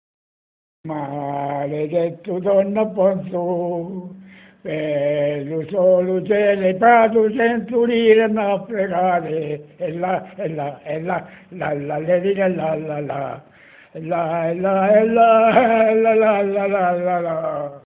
Una canzoncina di sberleffo fu intonata a suo carico (clic sul documento per ascoltarla).